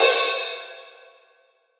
DDW3 OPN HAT 5.wav